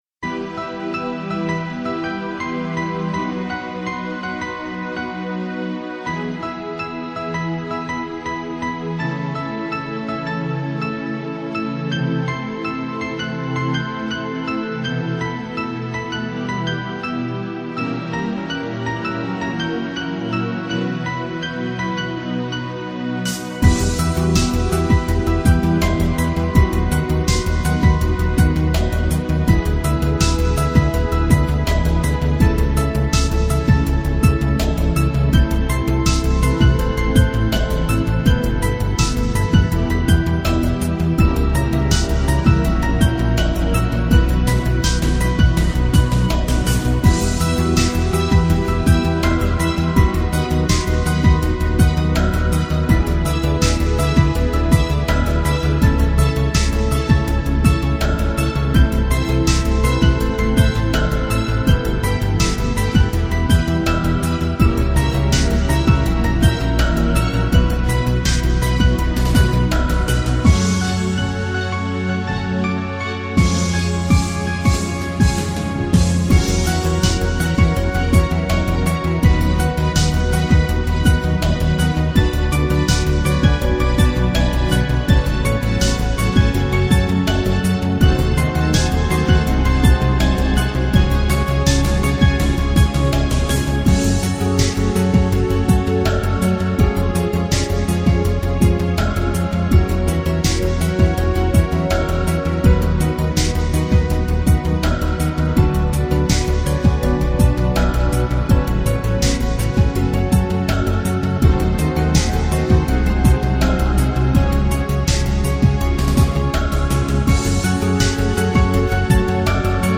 Шопен-Весенний вальс (открыта)